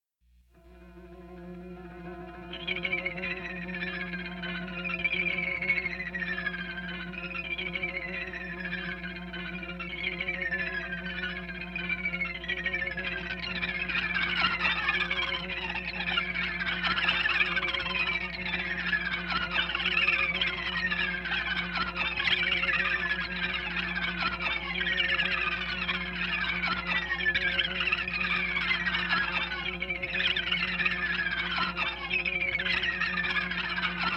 Genre: Math Rock.